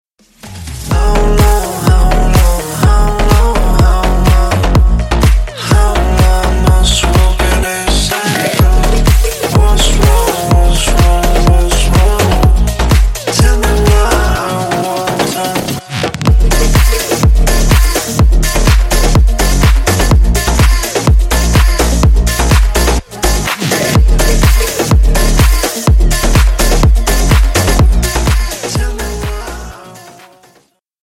Клубные Рингтоны » # Громкие Рингтоны С Басами
Танцевальные Рингтоны